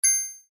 dice_6.mp3